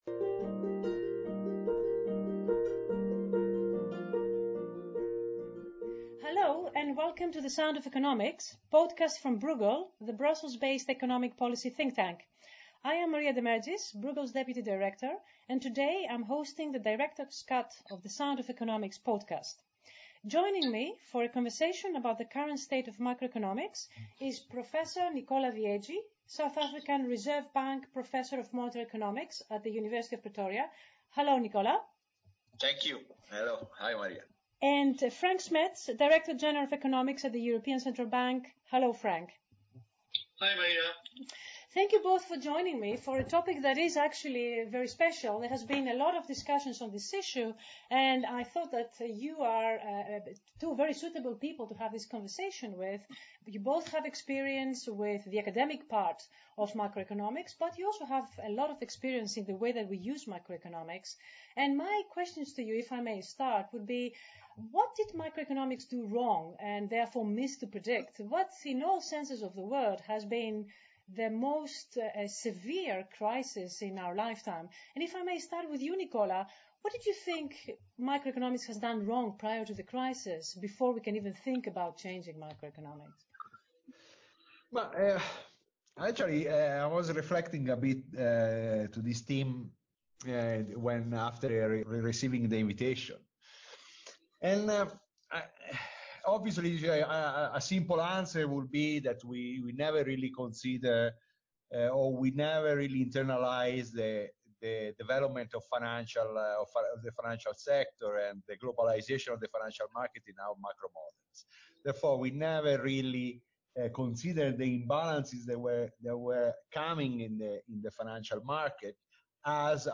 hosts a conversation with